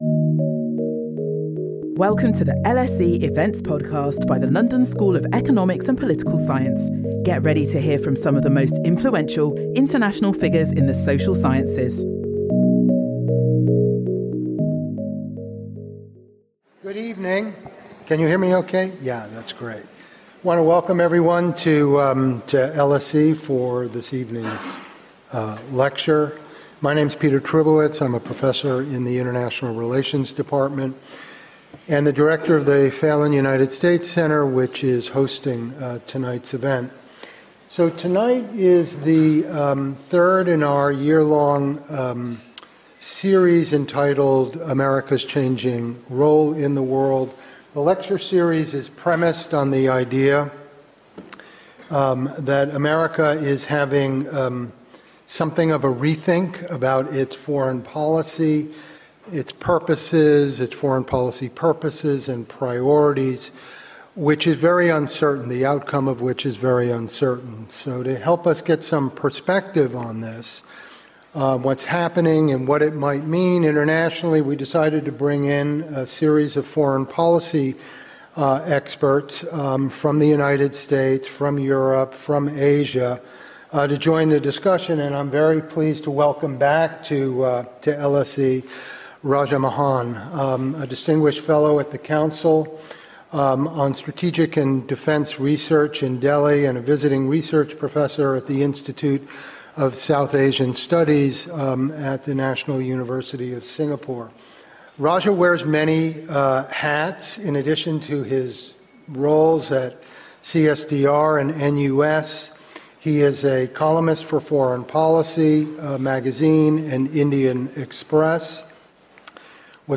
In this lecture, one of India’s leading strategic thinkers and commentators examines the roots of Donald Trump’s America First agenda and assesses its implications for the future of stability on the Eurasian landmass and its surrounding waters.